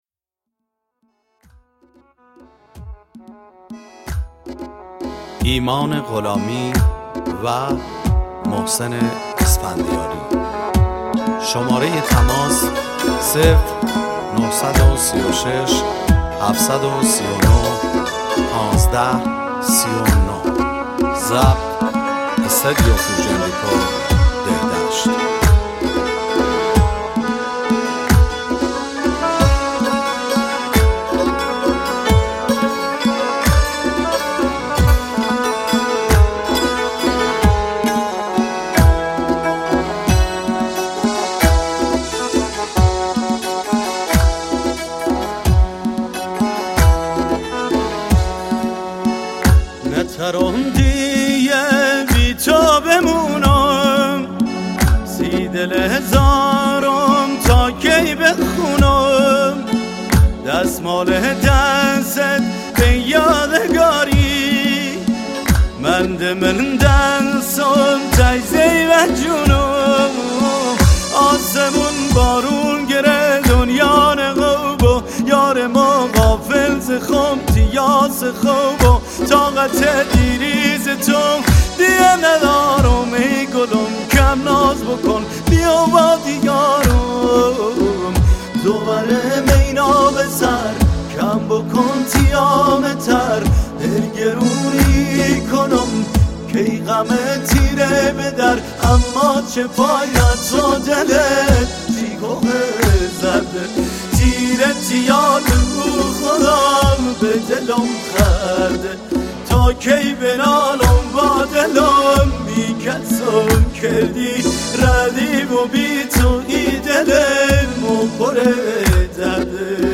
Lori song